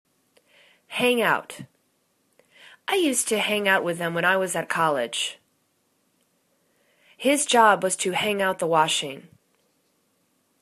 hang out     /hang owt/    phrasal verb